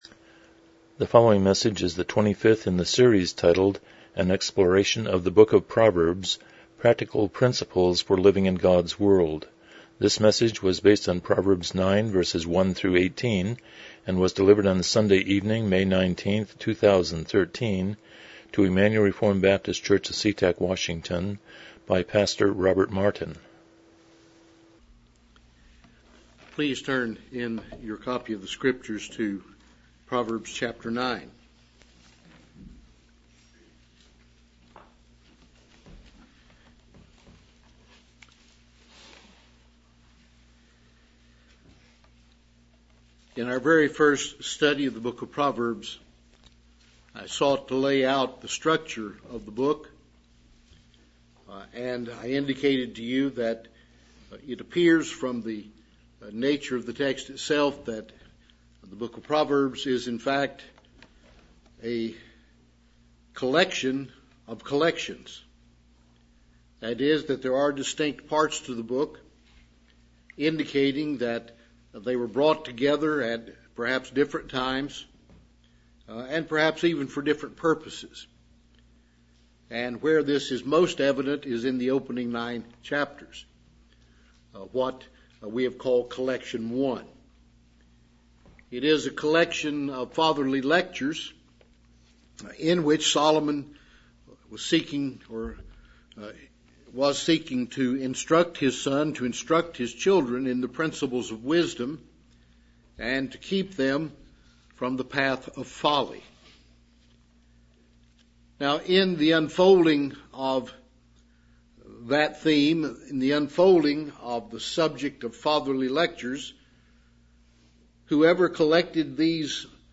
Proverbs 9:1-18 Service Type: Evening Worship « 29 The Sermon on the Mount